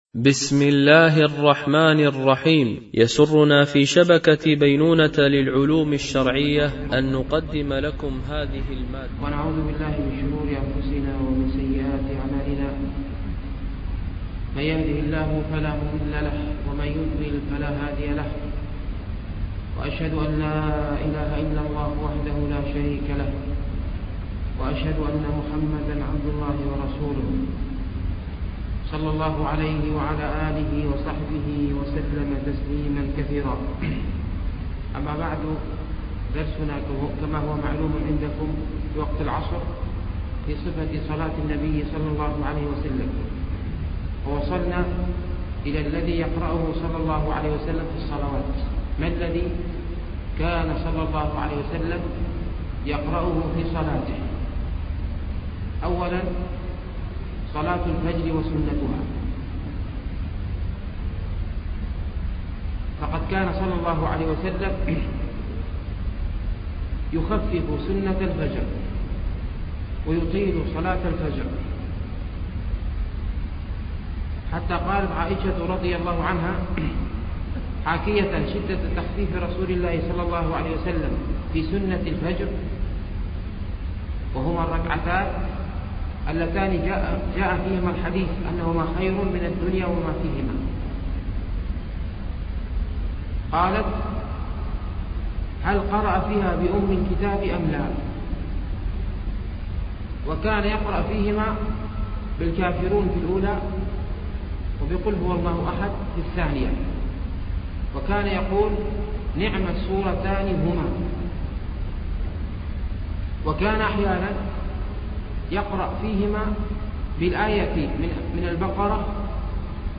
فقه الصلاة ـ الدرس السابع